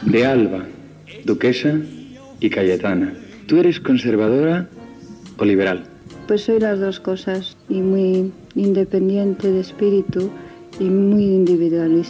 Fragment de l'entrevista a la duquesa d'Alba, Cayetana Fitz-James Stuart .